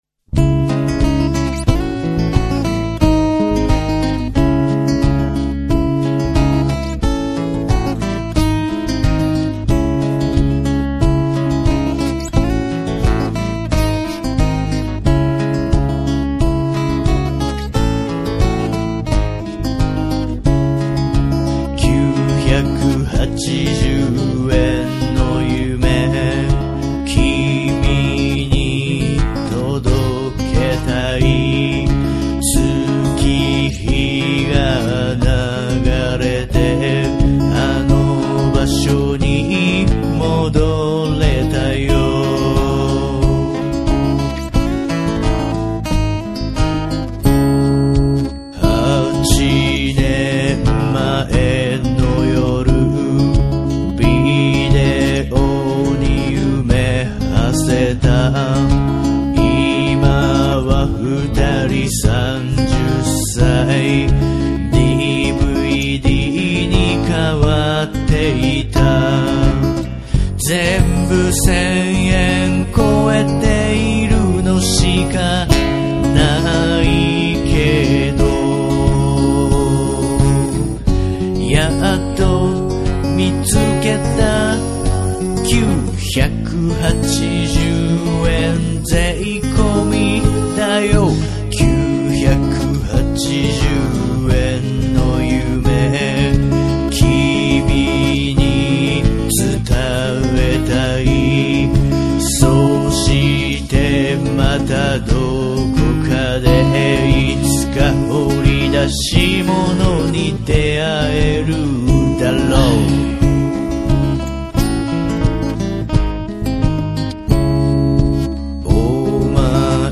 そこは16分で刻んでみました。
そしてこの曲、フルアコースティックです。(録音はデジタルですが。。。)
バスドラのような音はマイクを床においてその前で足踏みする音。
ギターはアコギのマイク録り。
歌は私の美声。コーラスも。
トライアングルなんかも使ってます。もちろんマイク録り。
あー、ベースだけエレキベースだわ。
すこし大人になったということで、こだわりのアコースティックサウンド。